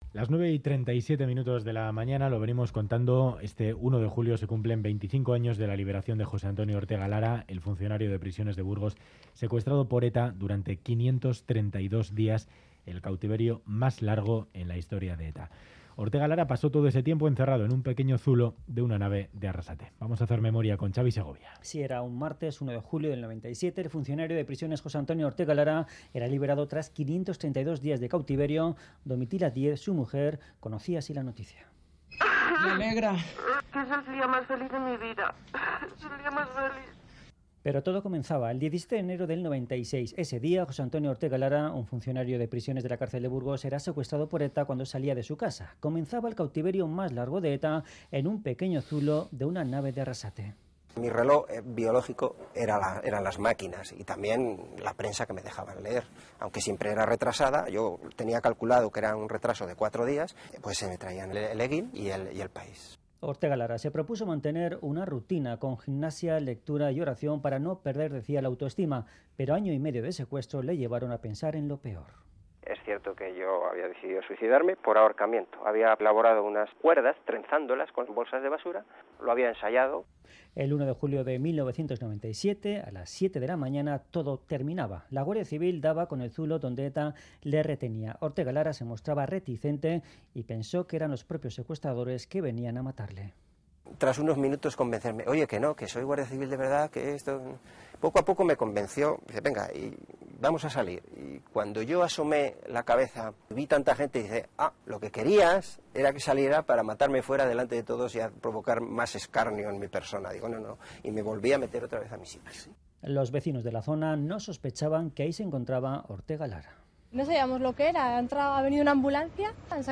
Conversamos con el Juez Baltasar Garzón, que coordinó el registro de la nave de Arrasate donde se encontró a Ortega Lara.
Radio Euskadi ENTREVISTA